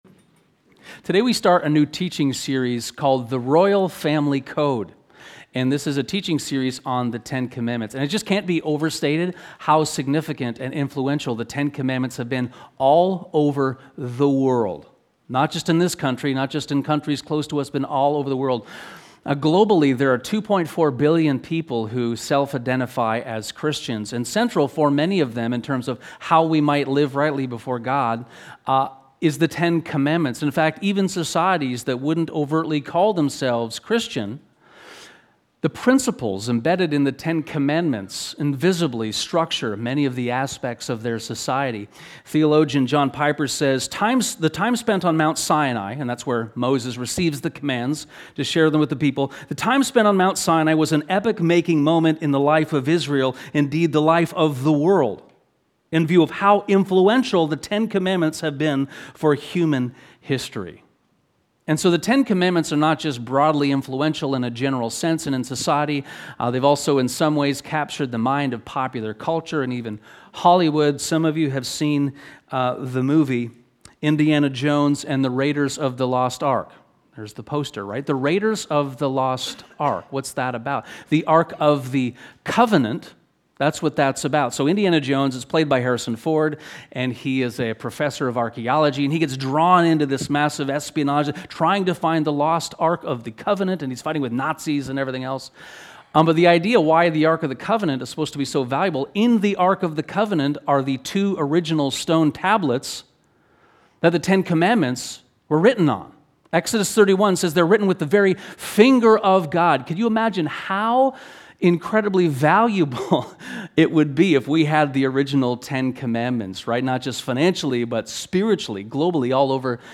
Sermons | Westminster